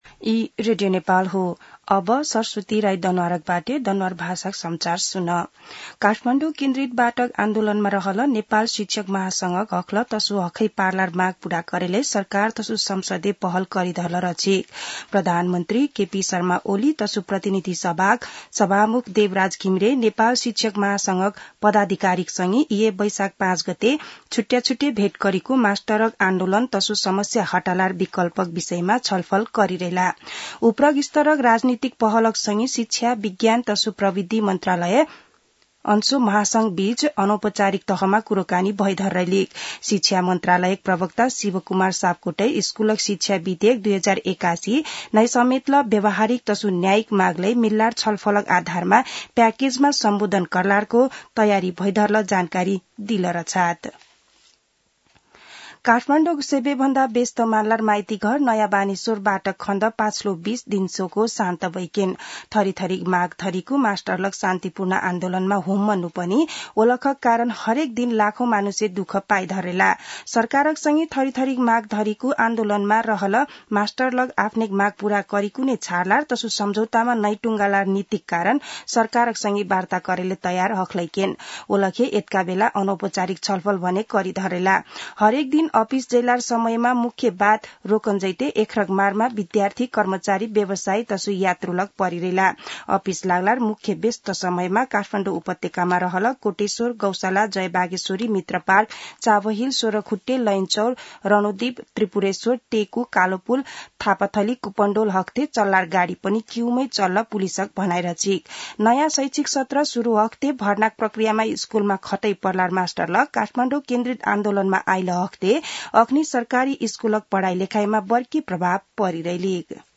दनुवार भाषामा समाचार : ८ वैशाख , २०८२
danuwar-news-1-7.mp3